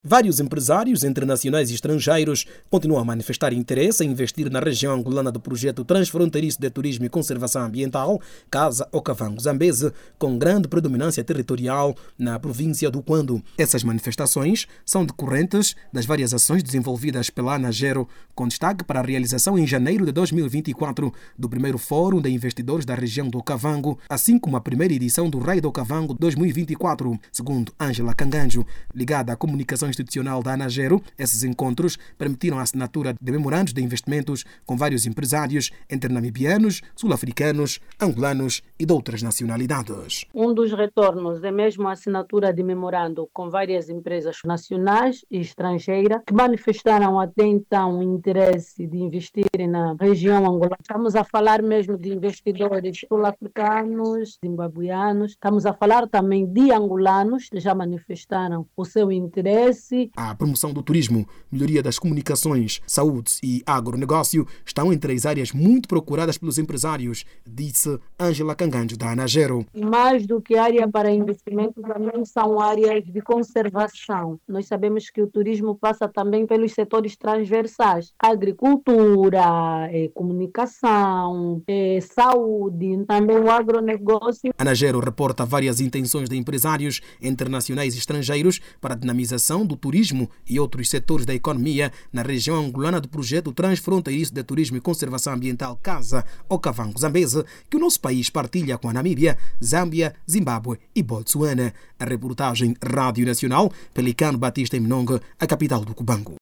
A componente angolana do Projecto Transfronteiriço de Turismo e Conservação Ambiental – Kaza-Okavango-Zambeze, continua a despertar o interesse de empresários nacionais e estrangeiros. A iniciativa é promover o desenvolvimento sustentável por meio do turismo ecológico e preservação ambiental. Clique no áudio abaixo e ouça a reportagem